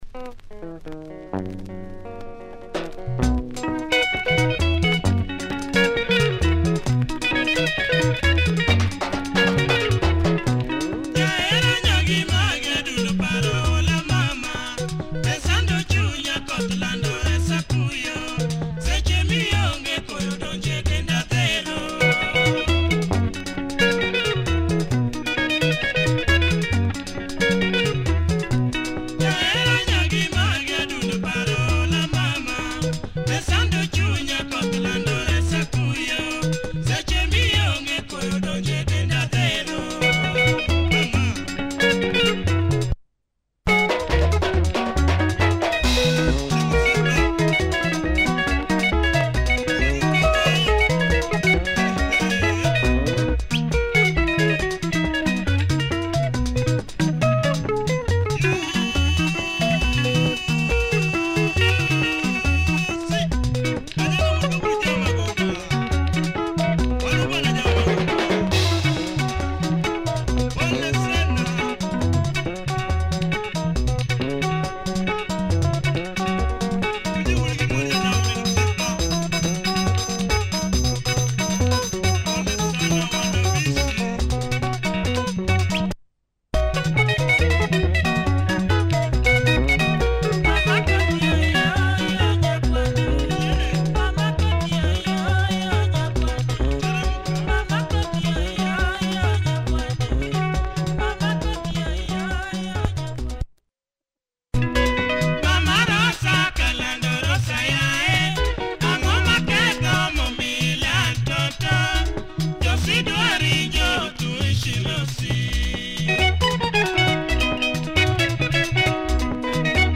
Nice LUO Benga, bumping tempo, party breakdown